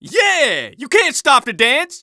The Scout starts dancing on the ground while saying or singing: